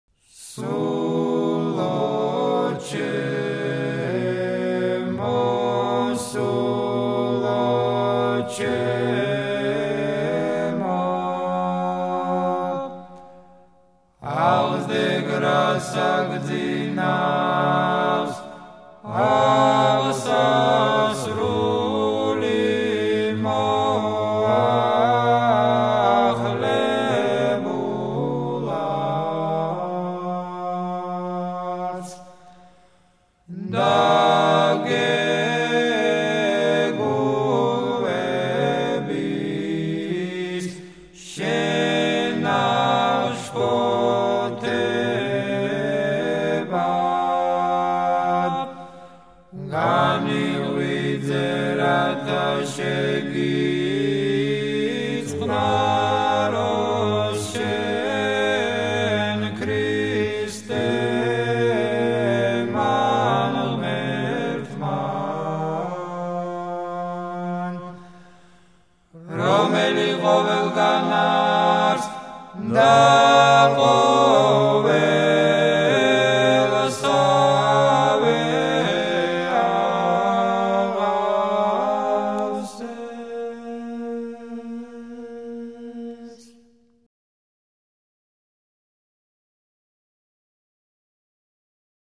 საგალობელი: სულო ჩემო გუნდი: ჯიხეთის დედათა მონასტრის გუნდი